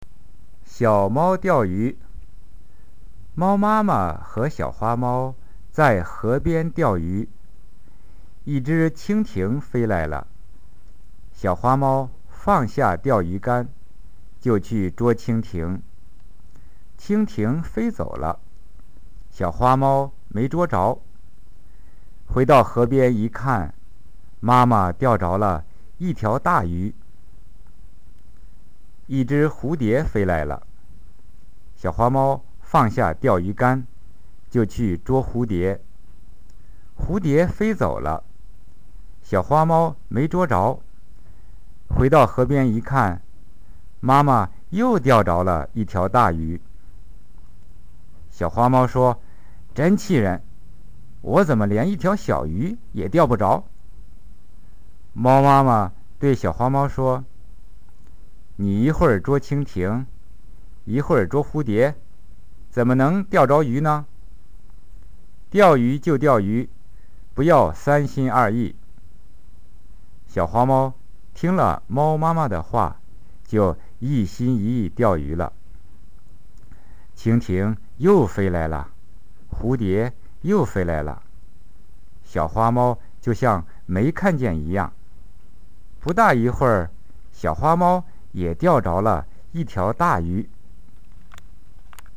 発音を聞く　（１分半：ふつう） 　　　発音を聞く　（２分：ゆっくり）